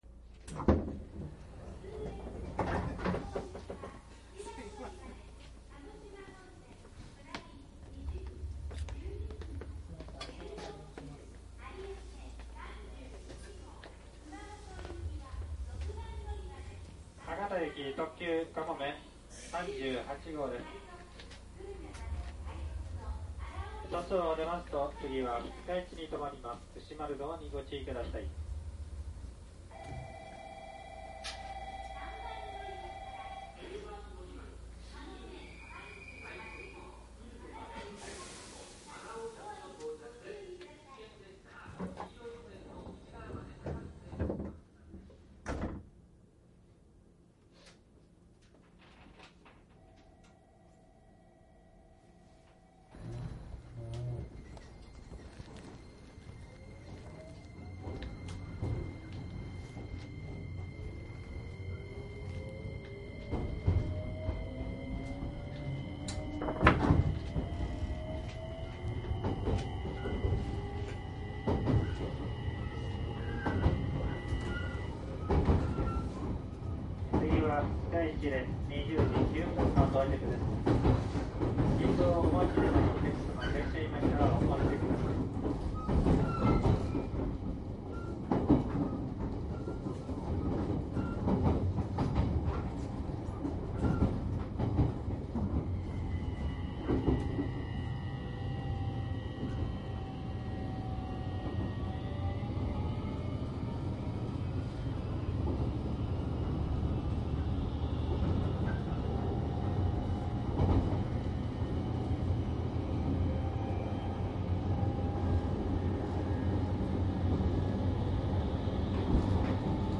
鉄道の走行音を収録したCDです。 高音質デジタル音源でリアルな乗車気分をご家庭のCDプレーヤーで味わえます。
■収録内容 【収録車両】 モハ885-6／7 【収録区間】 DISK1・2 JR長崎本線 特急かもめ10号 長崎→佐賀 DISK2 JR長崎本線 特急かもめ38号 佐賀→博多 【モーター音】 （日立製VVVFで収録） サンプル音声 特急かもめ38号.mp3 【収録機材】 ソニーDAT 【収録マイク】 ソニーECM959 【収録日】 2005年1月25日／2月27日 ※商品は新品です。